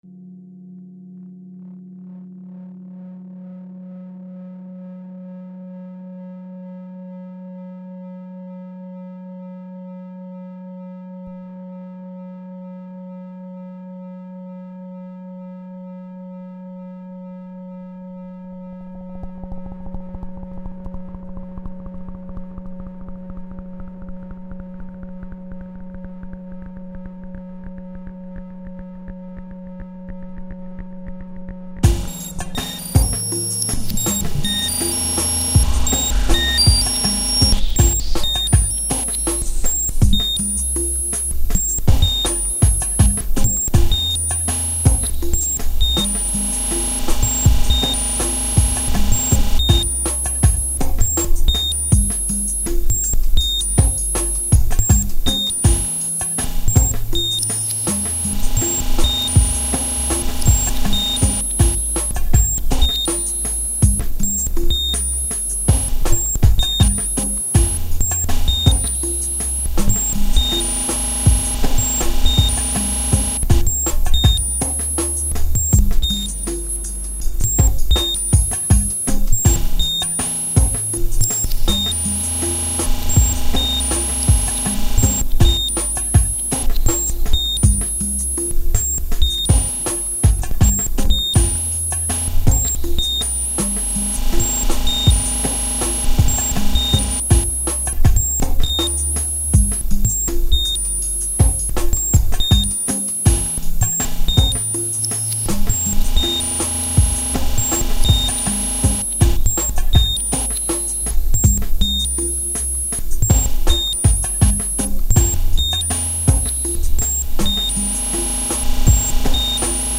File under: Avantgarde
shifting around beats and rhythmic textures